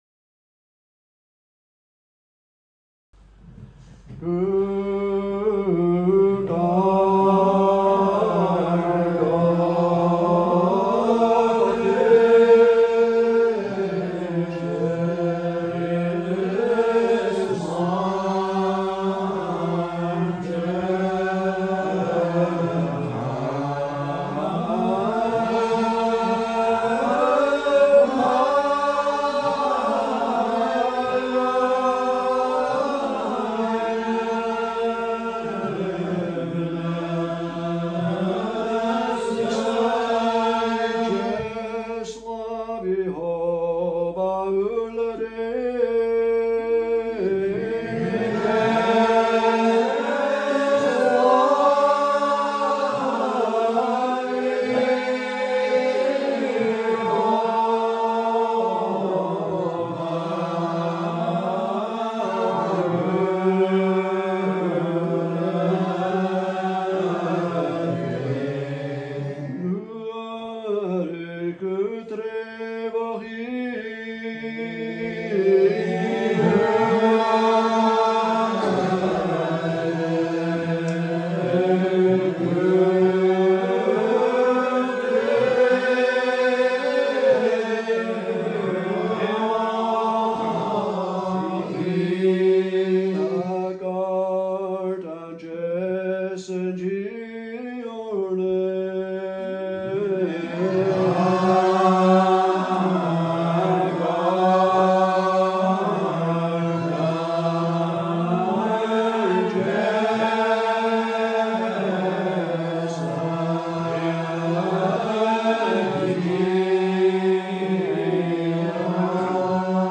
Gaelic Psalmody - class 3